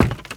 High Quality Footsteps
STEPS Wood, Creaky, Run 14.wav